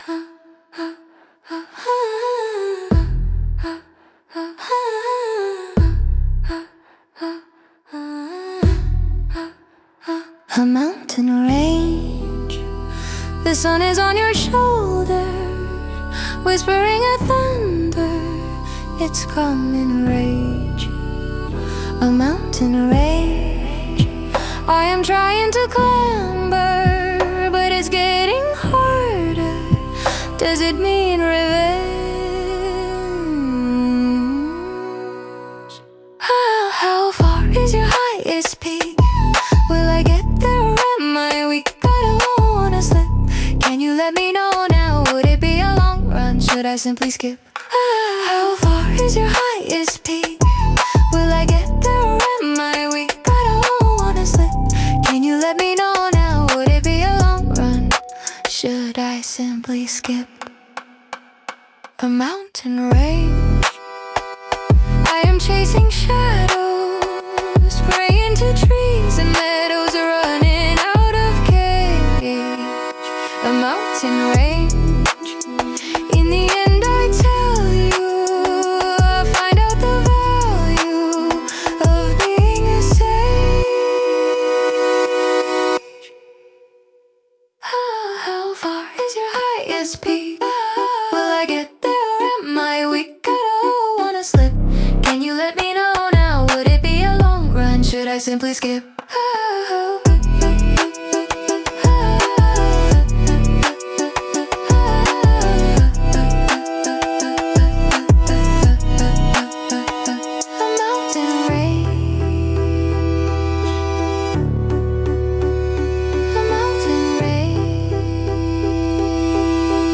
Пример 3: Трэп-бит с авторским верхом
трэп, средний темп, плотный низ, дробь хэтов, резкие акценты, короткие сбросы перед припевом, энергичный припев